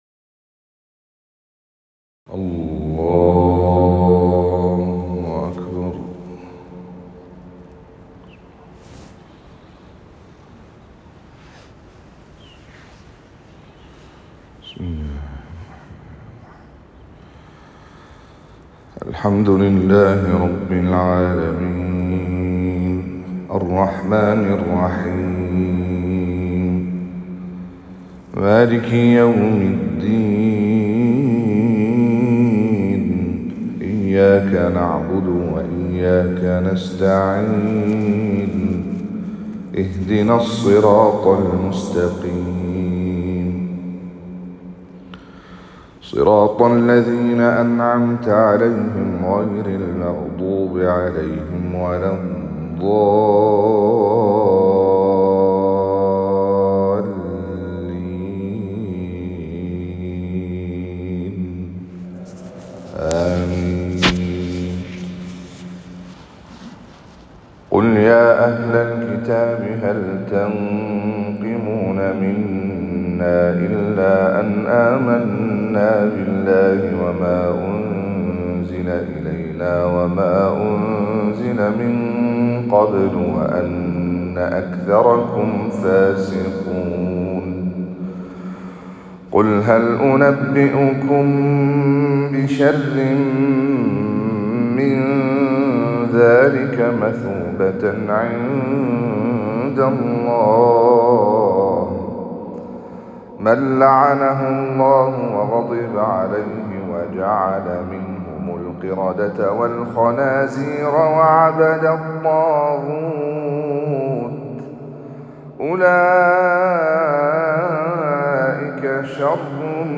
صلاة الفجر ٢٢ ذو الحجة ١٤٤٦هـ